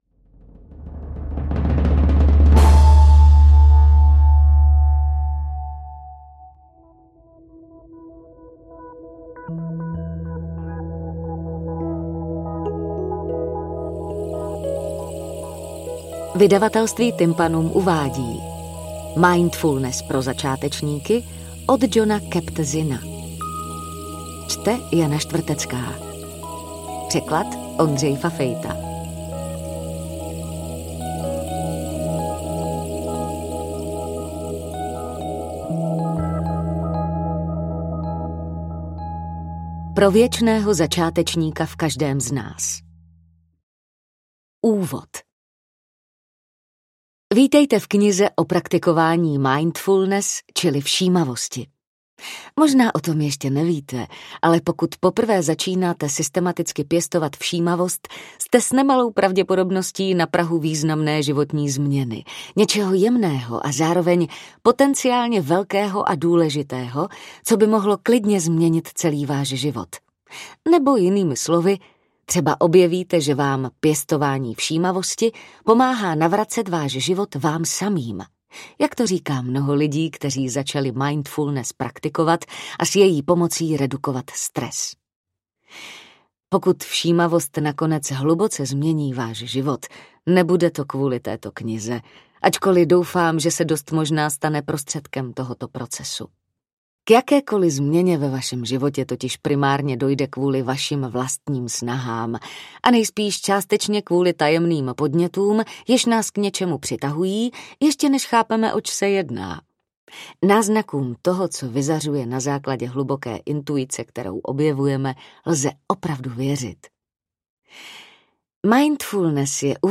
AudioKniha ke stažení, 12 x mp3, délka 3 hod. 51 min., velikost 211,6 MB, česky